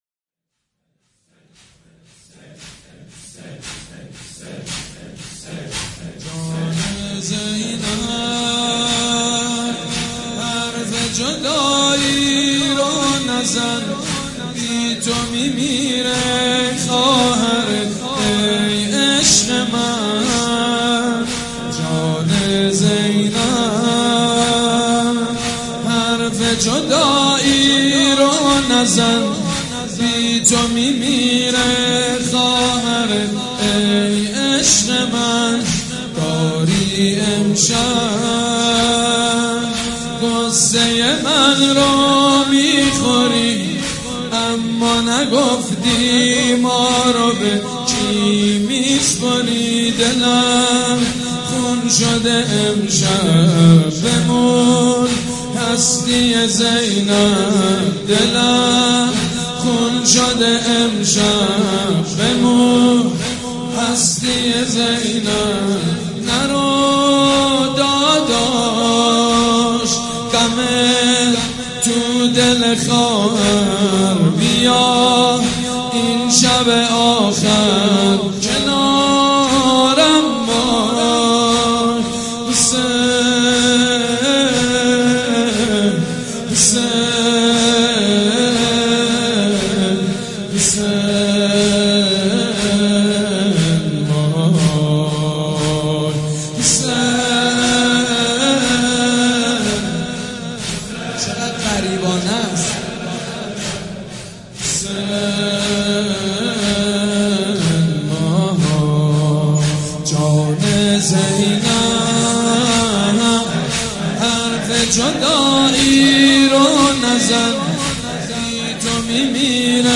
جان زینب حرف جدایی رو نزن بی تو میمیره خواهرت ای عشق من _ زمینه سيد مجيد بنی فاطمه شب تاسوعا